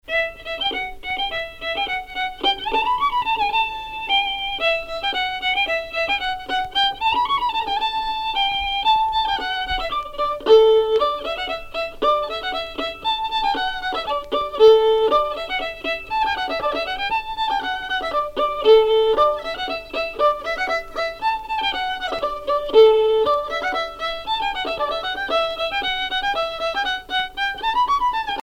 danse : angoise, maristingo
Genre brève